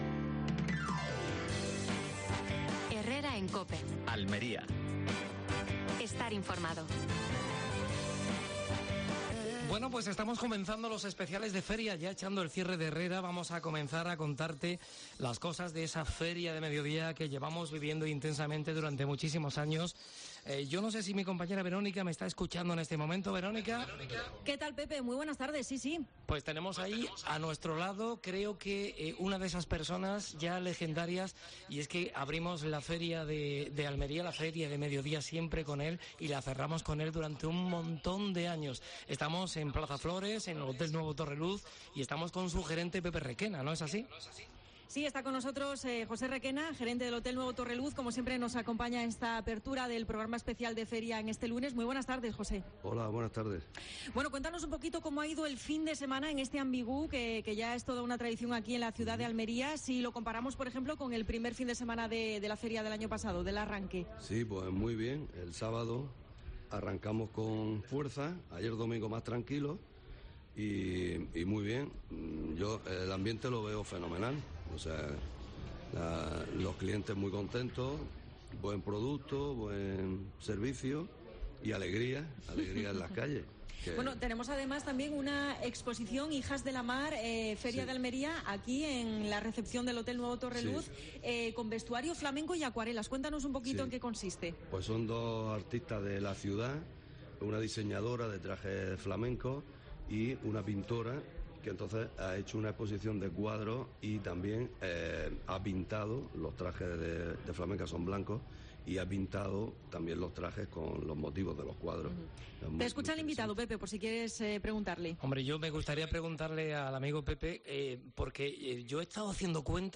AUDIO: Programación especial desde el Hotel Torreluz con motivo de la Feria de Almería.
Entrevista